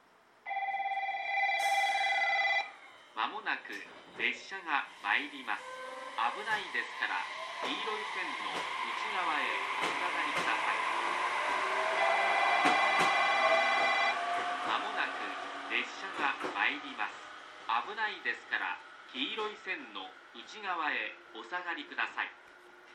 この駅では接近放送が設置されています。
１番のりば日豊本線
接近放送普通　中津行き接近放送です。